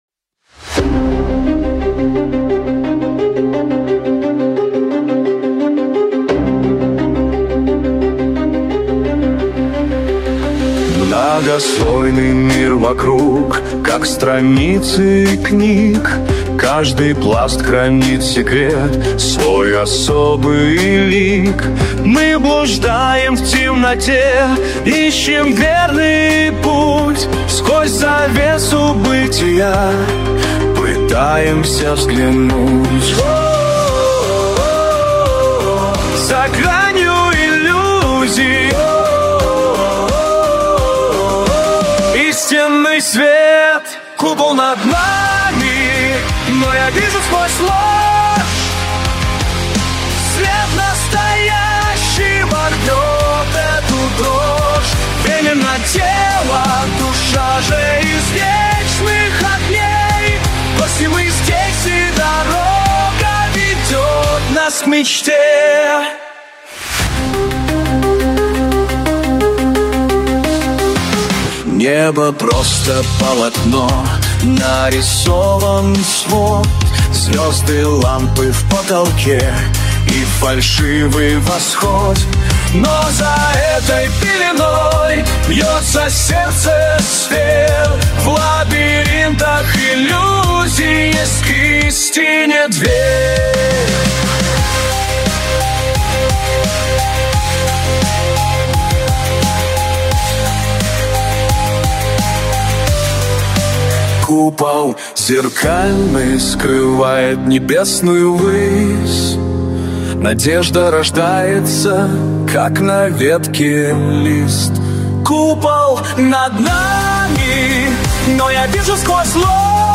Жанр: Alternative Rock